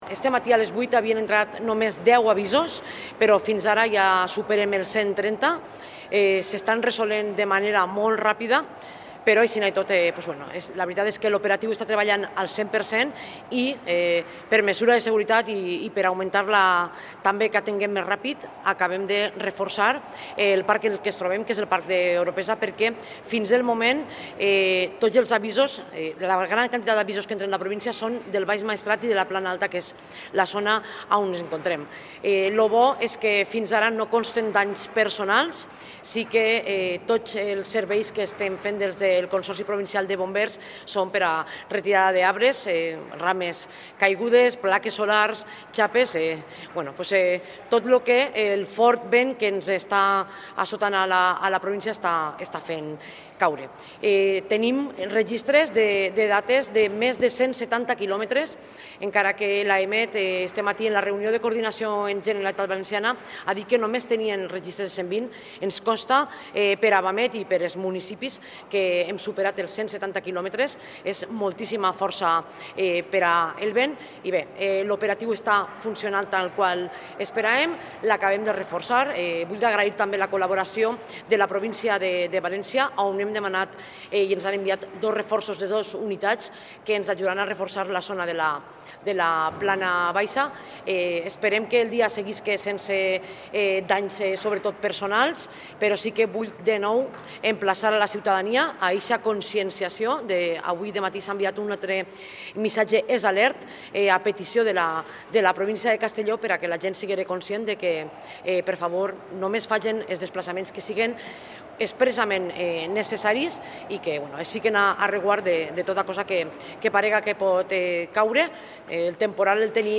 En la seua compareixença, Marta Barrachina ha demanat a la població “informar-se a través dels canals oficials” de l’evolució del temporal i de les alertes per vent i actualitzacions emeses pel Centre de Coordinació d’Emergències de la Generalitat, a més de consultar la informació meteorològica oficial d’Agència Estatal de Meteorologia (AEMET).